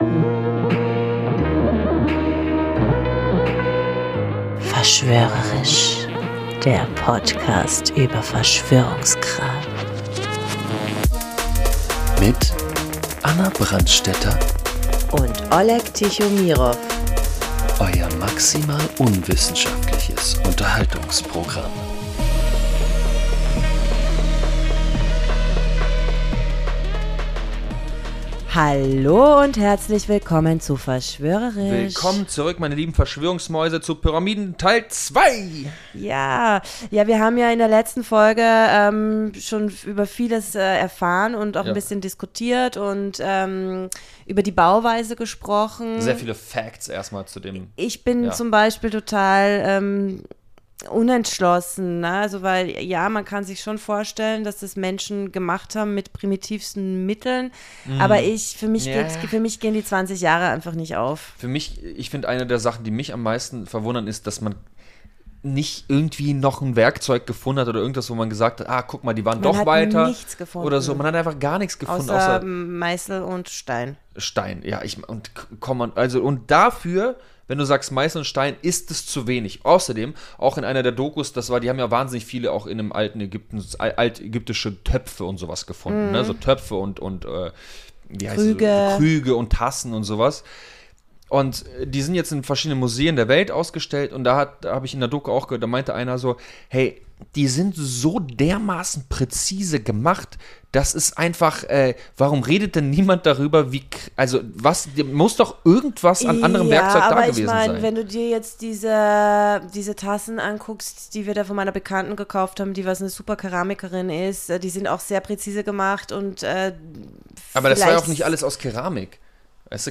Wie immer mit einer guten Portion Halbwissen, falsch ausgesprochenen Namen und Humor.